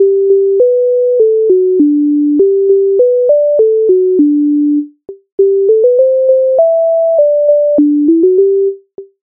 MIDI файл завантажено в тональності G-dur
Ой під вишнею під черешнею Українська народна пісня використана в опері "Наталка-Полтавка" М.Лисенка як пісня Виборного
Ukrainska_narodna_pisnia_Oj_pid_vyshneyu_pid_chereshneyu.mp3